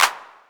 Southside Clapz (16).wav